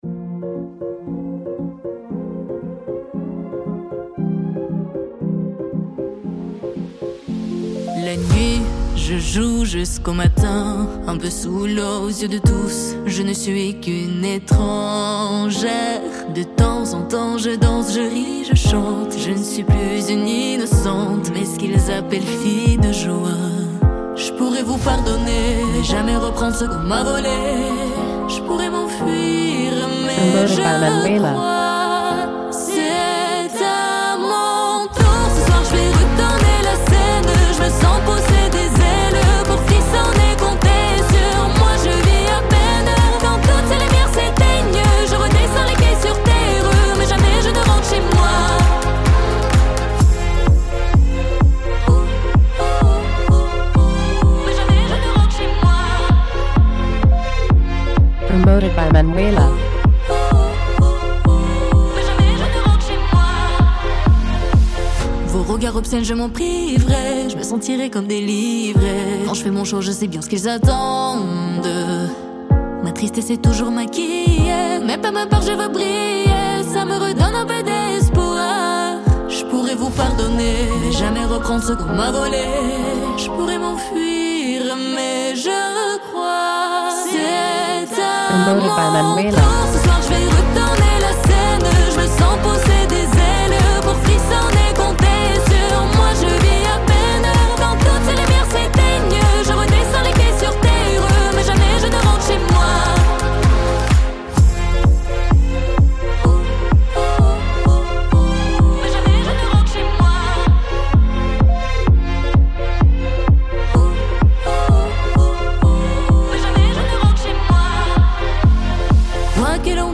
Radio Edit